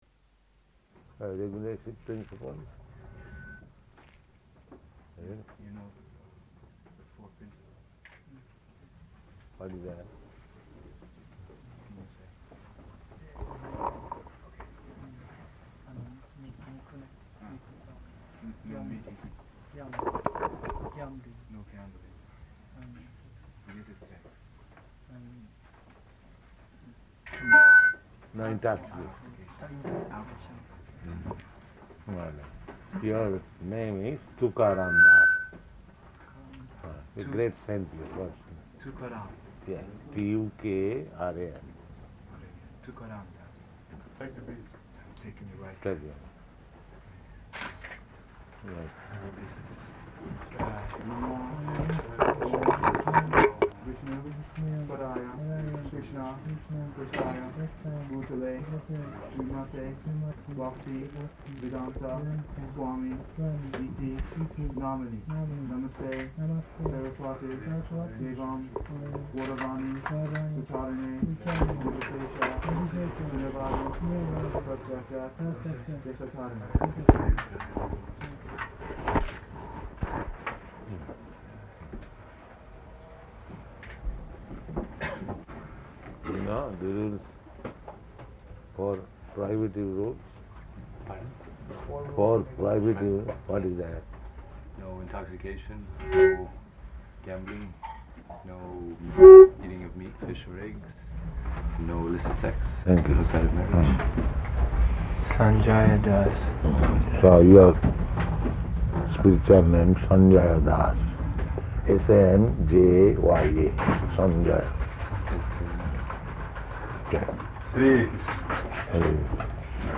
-- Type: Initiation Dated: April 30th 1972 Location: Tokyo Audio file